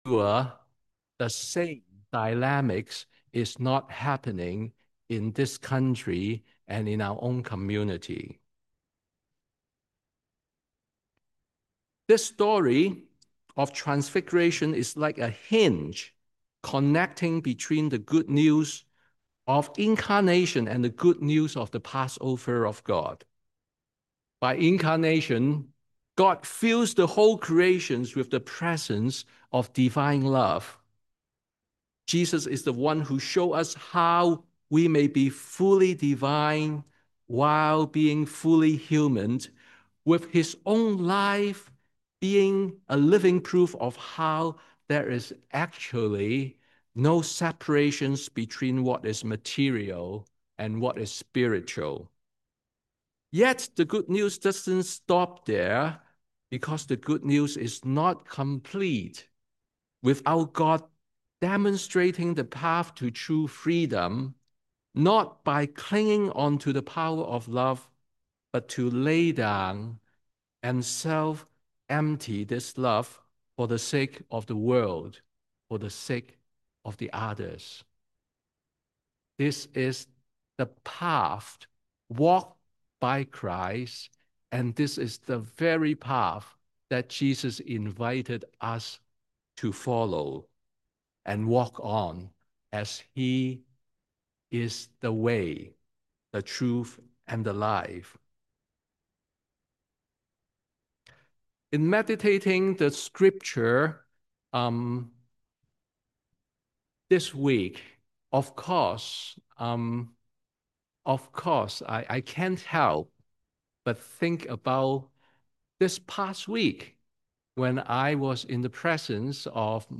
Sermon on the Last Sunday of Epiphany